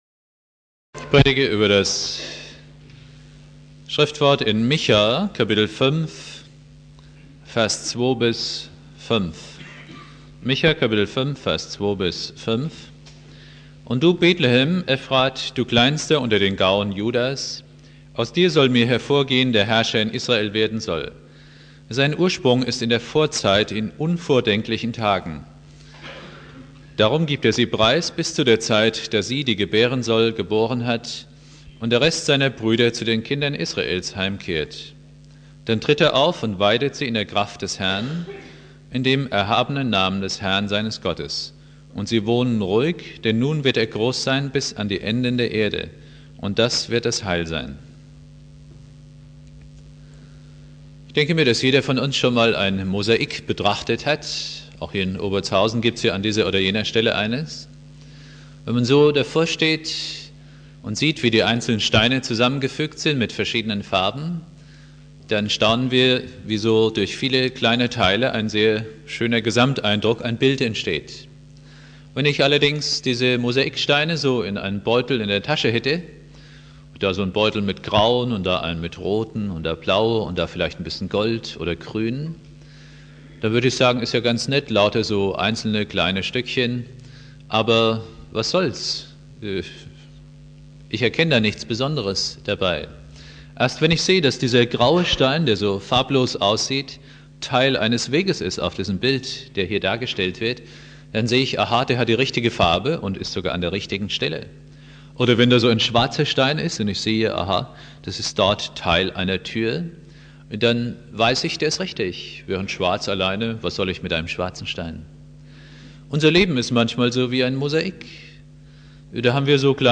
Predigt
1.Weihnachtstag Prediger